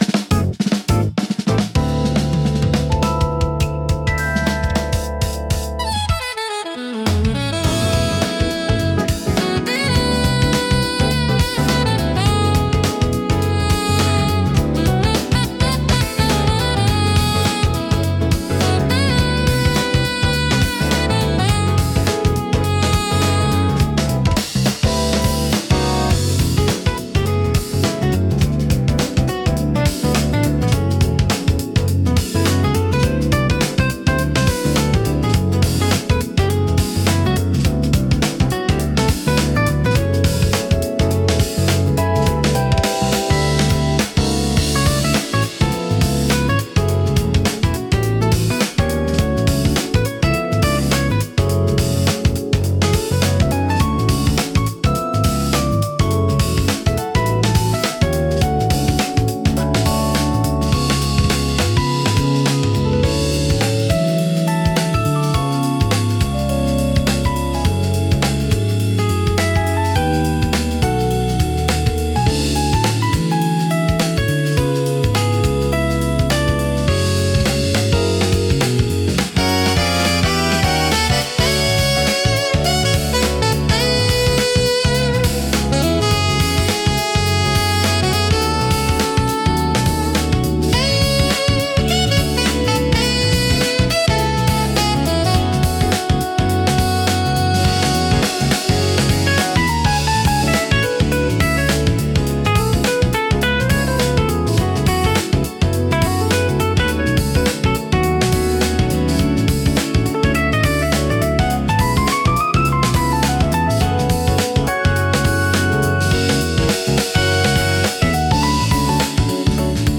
落ち着いた空気感を作り出しつつも、聴き疲れしにくい快適さがあり、ゆったりとした時間を楽しみたいシーンで多く活用されます。